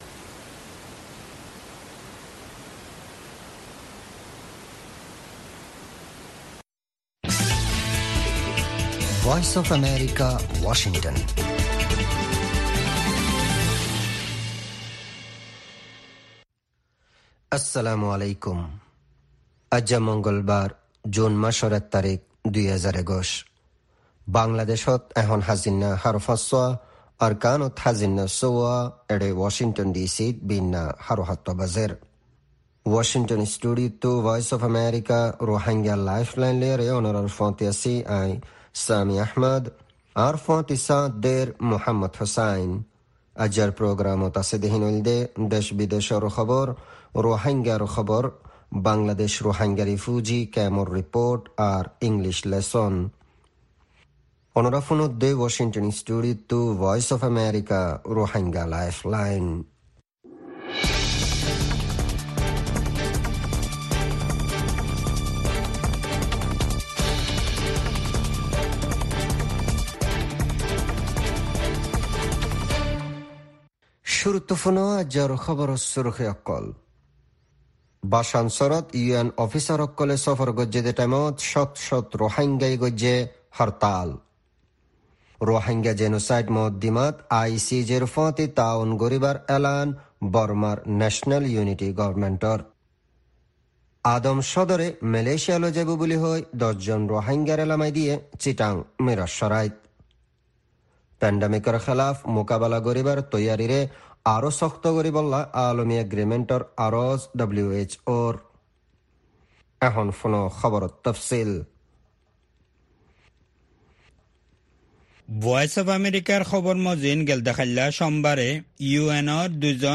Rohingya “Lifeline” radio